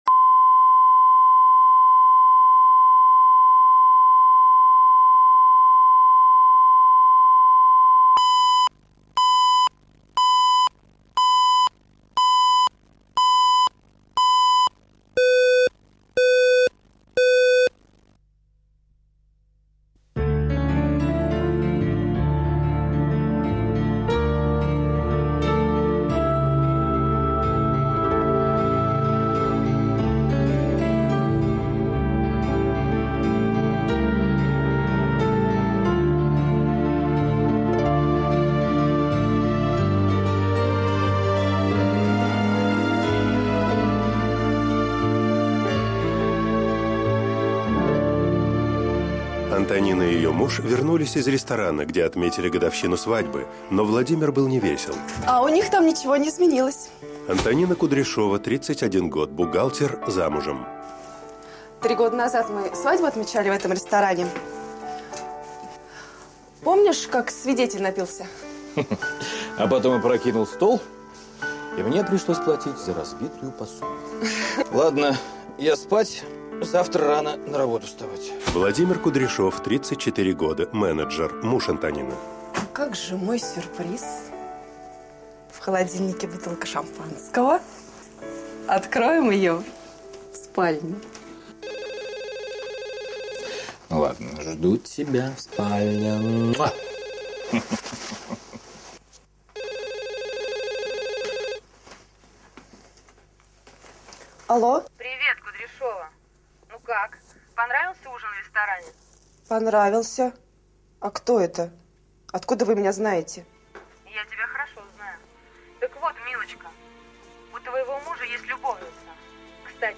Аудиокнига Возврату не подлежит | Библиотека аудиокниг